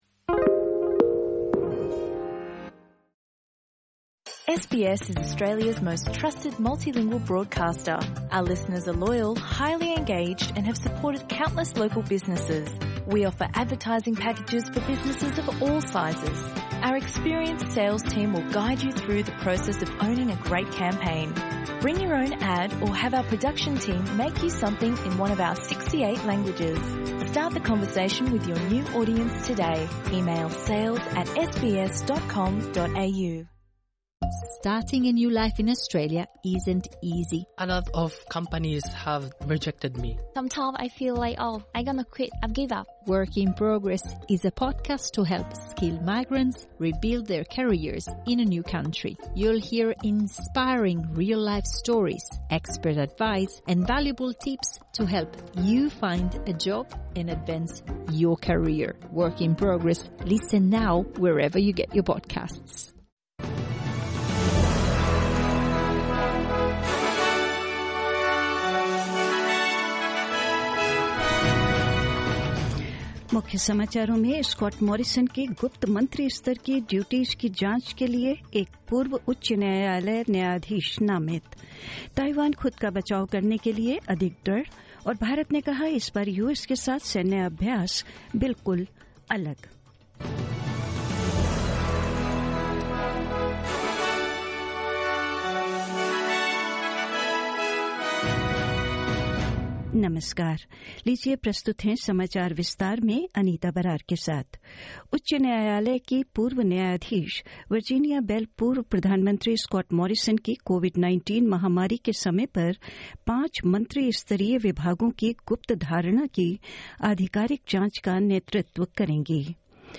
SBS Hindi News 26 August 2022: Former High Court judge appointed to head secret ministries inquiry